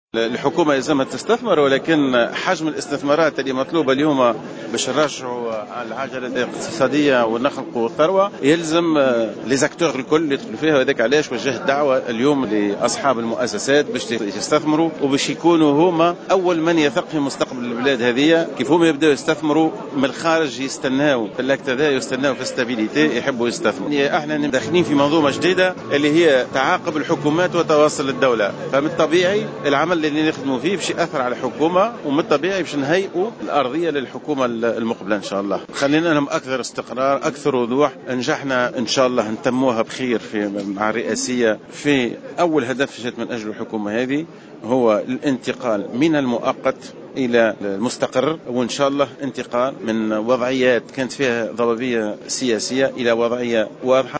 Le chef du gouvernement actuel Mahdi Jomaa a affirmé aujourd’hui dans une déclaration accordée à Jawhara FM lors de la 29ème session des journées de l’entreprise que sa gouvernance a assuré la stabilité et la passation de la période de transition à la deuxième république Tunisienne.